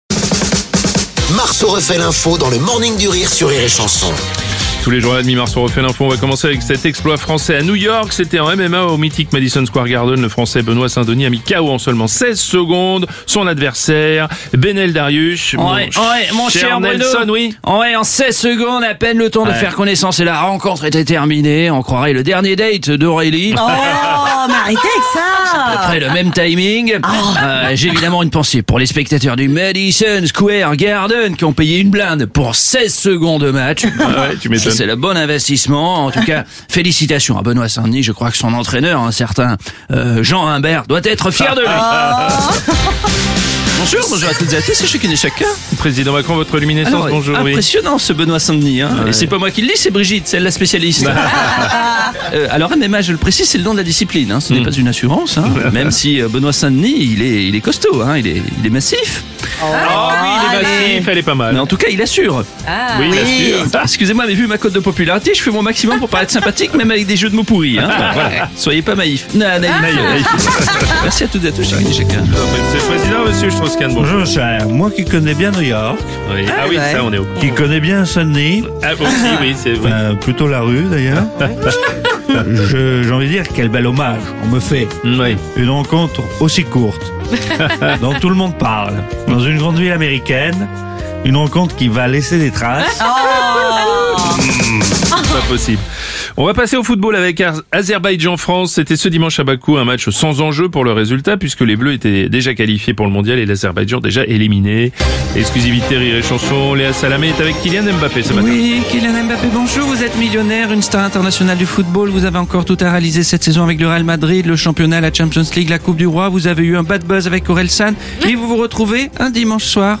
L’imitateur